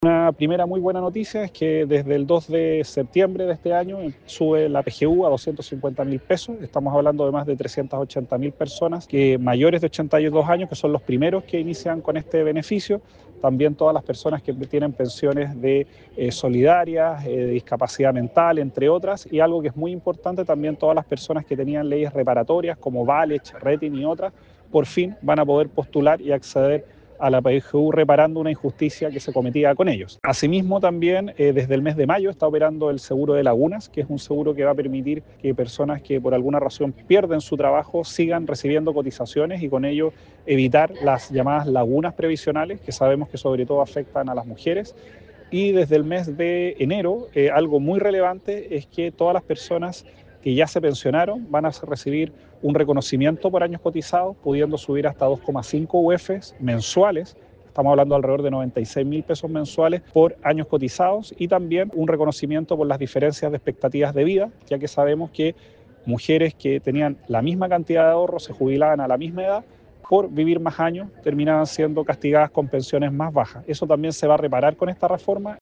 En ese contexto, el ministro del Trabajo y Previsión Social, Giorgio Boccardo, encabezó este martes un conversatorio ciudadano en la Universidad de Concepción, indicando que es “una reforma que tras más de una década de discusión, hoy puede decirle al país que van a subir las pensiones”.